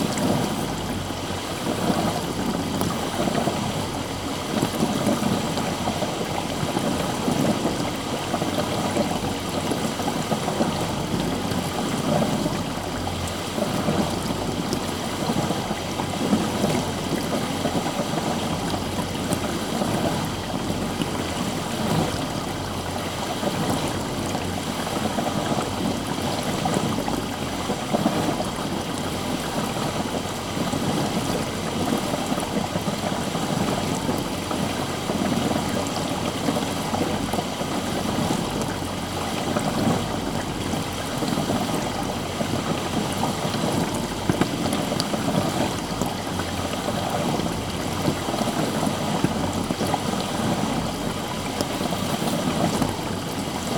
environnement_02.wav